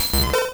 Cri de Ptitard dans Pokémon Rouge et Bleu.